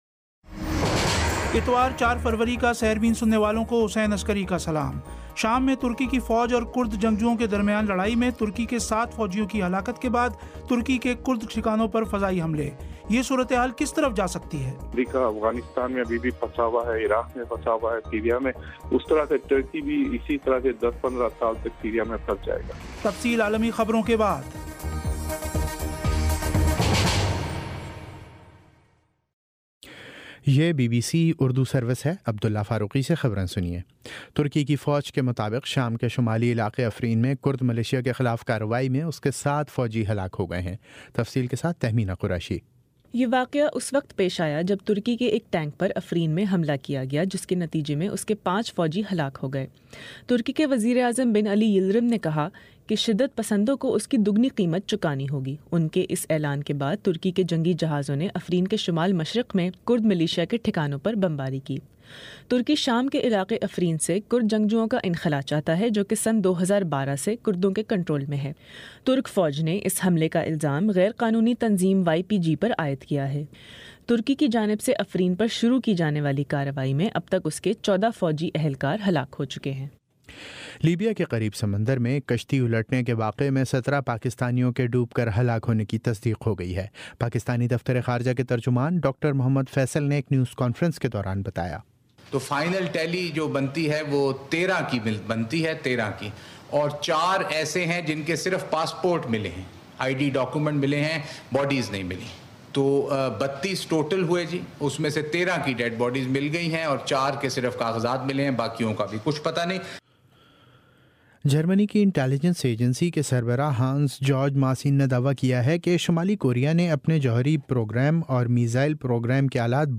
اتوار 04 فروری کا سیربین ریڈیو پروگرام